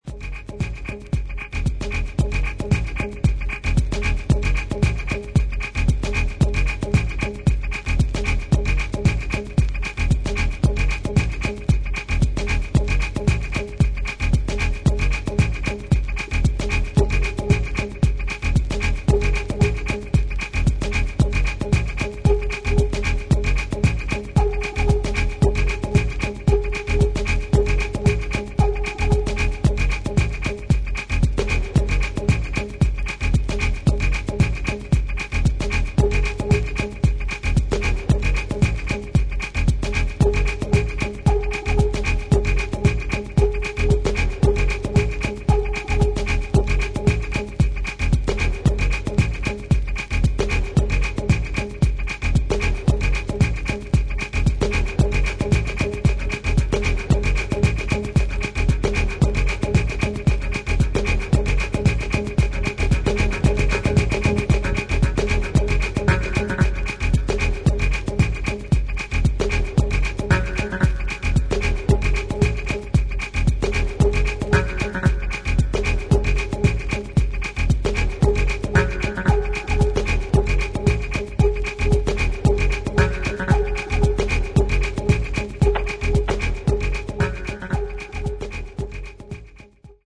低音の音圧の野太さにも驚かされる一枚。